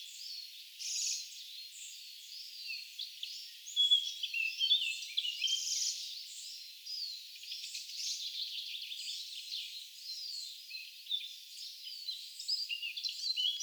erikoinen räkättirastaan poikasen ääni?
tuollainen_ilm_rakattirastaan_poikasen_aani.mp3